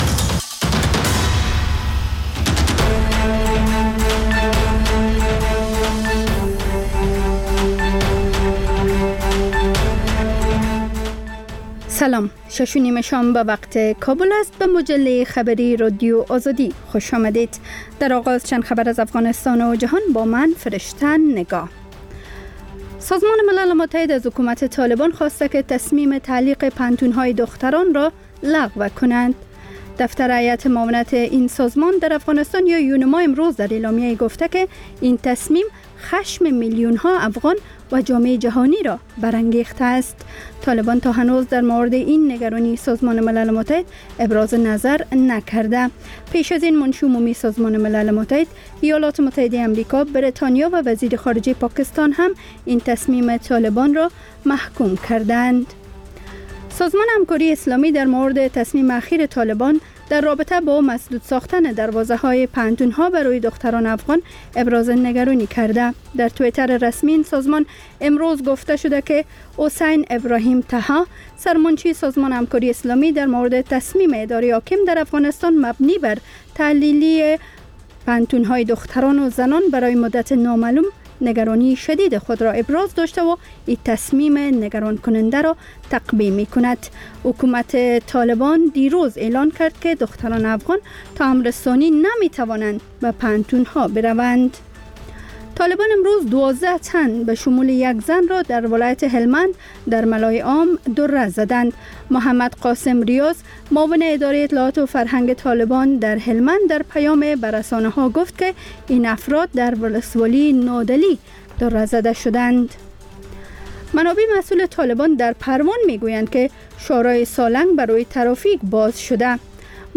مجله خبری شامگاهی